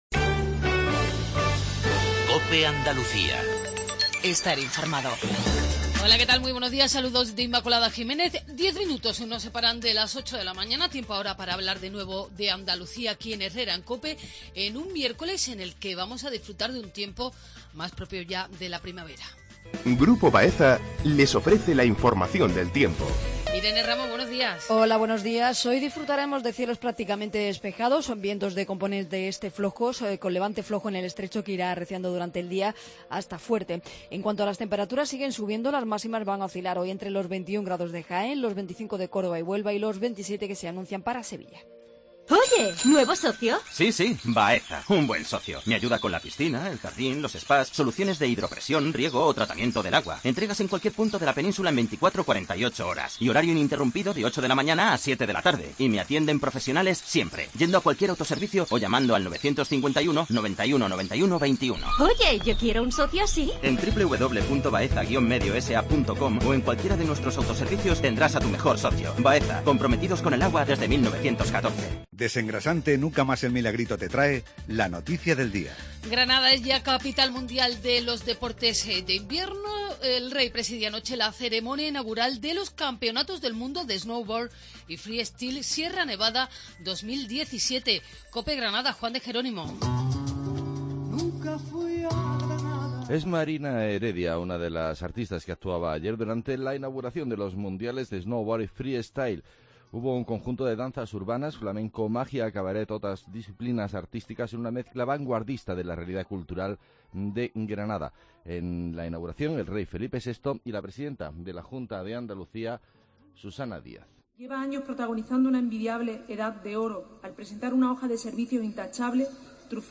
INFORMATIVO REGIONAL/LOCAL MATINAL 7:50